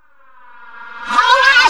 VOXREVERS1-L.wav